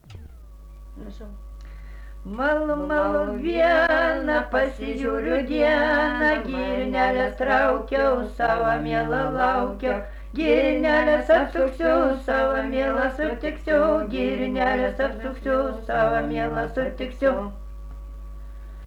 daina
Atlikimo pubūdis vokalinis